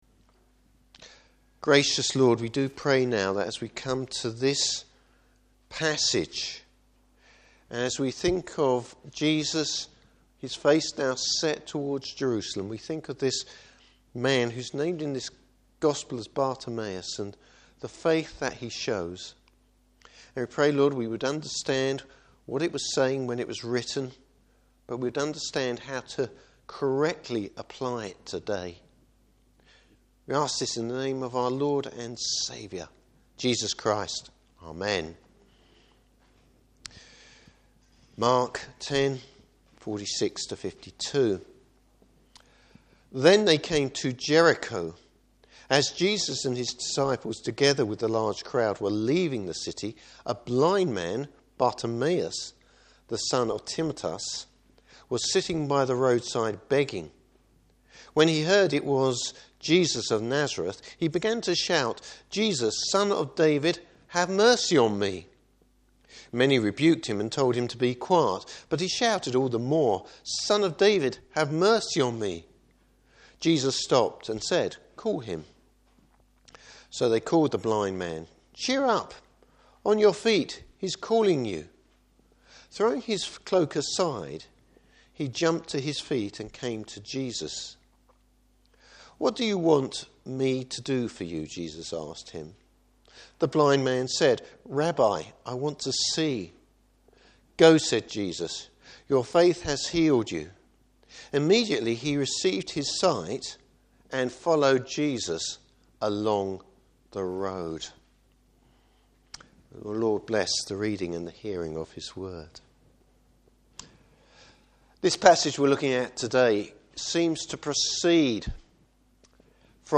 Service Type: Morning Service Real faith demonstrated.